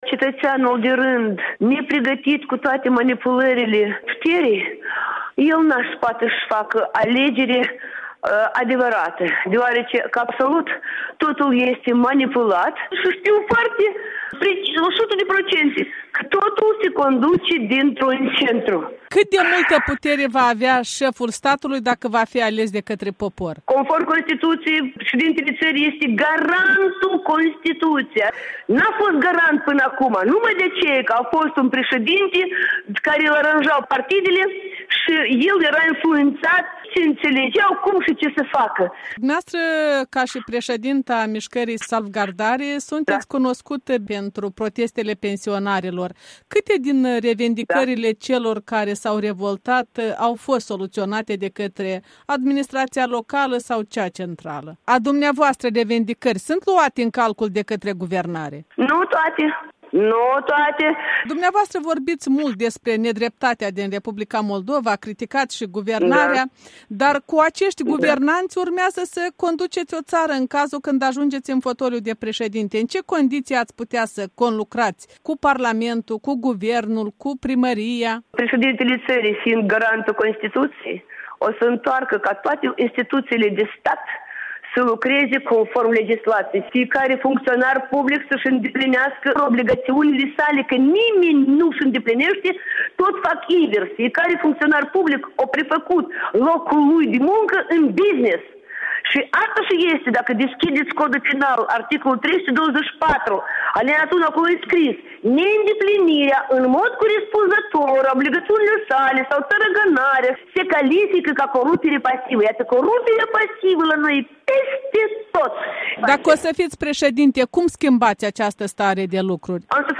Interviu electoral